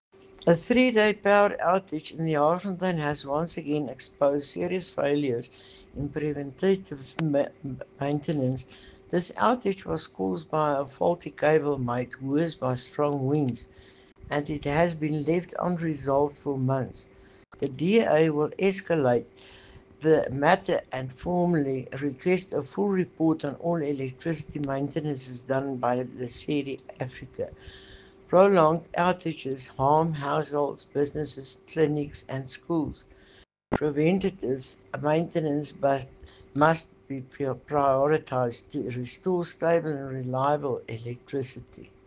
English and Afrikaans soundbites by Cllr Estelle Noordman and